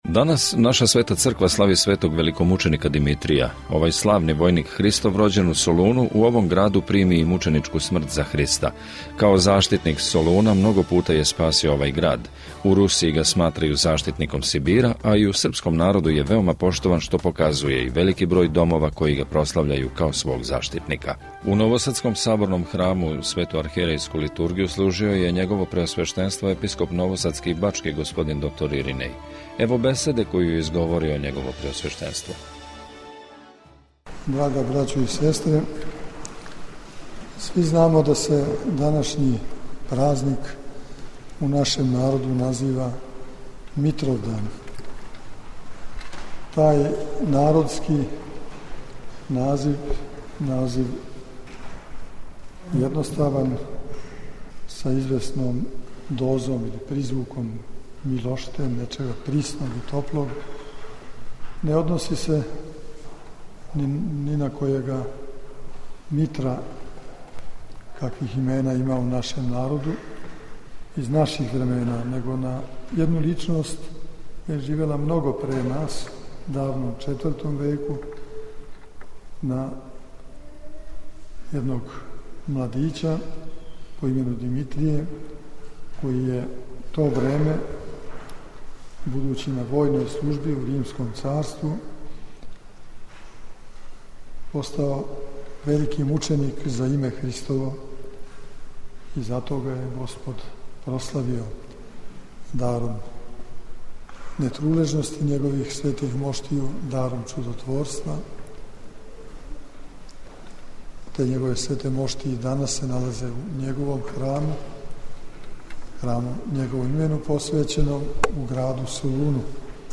Нови Сад - У новосадском Саборном храму, свету архијерејску Литургију служио је Његово Преосвештенство Епископ бачки Господин др Иринеј.